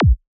Here You can listen to some of the Kicks included in the sample library:
• Versatile and Dynamic: Whether you're producing trance, psytrance, or any other high-energy electronic music, these kicks will add that essential punch to your tracks.
KICK-VII-183.wav